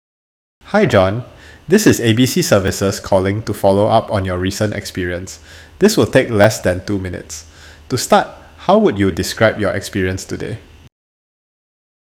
Preview AI Voice Agent
English | Singapore Accent
Human-like voice conversations that ask intelligent follow-up questions and extract richer insights